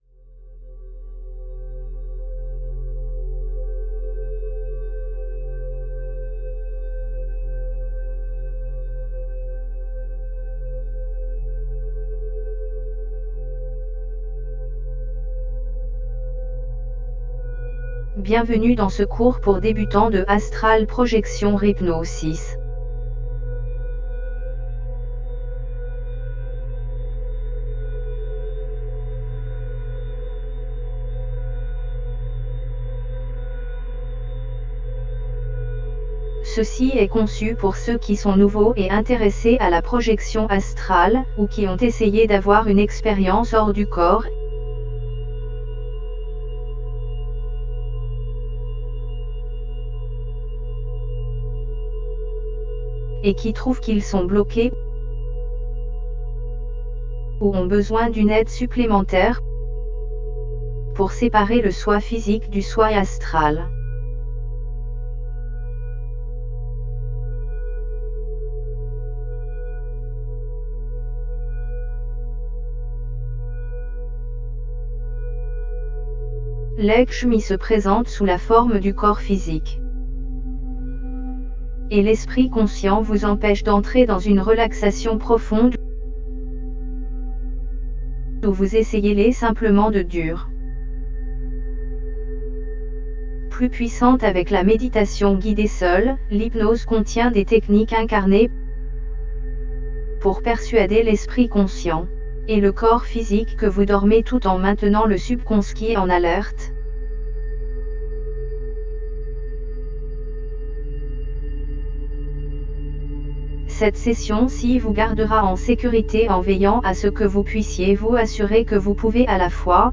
Cette hypnose et méditation de projection astrale est destinée à ceux qui sont nouveaux dans la projection astrale ou qui luttent pour atteindre l'état d'esprit permettant de séparer le soi astral du soi physique.
OBE1BeginnersAstralProjectionHypnosisMeditationFR.mp3